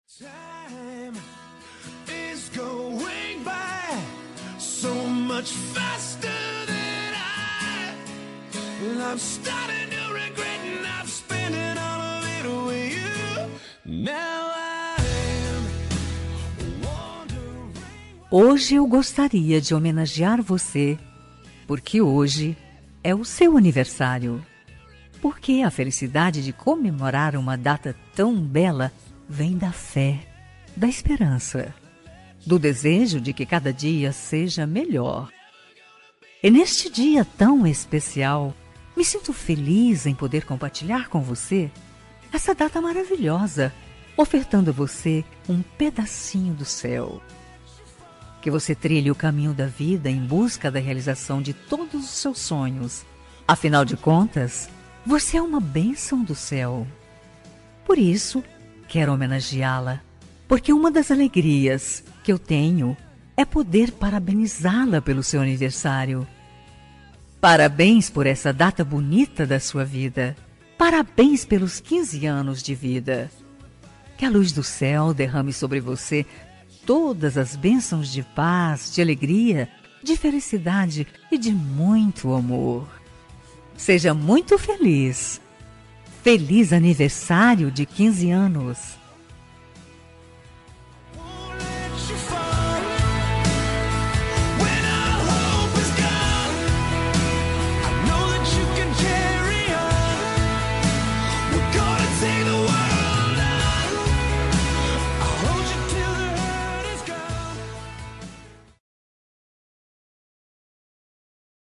Aniversário de 15 anos – Voz Feminina – Cód: 33368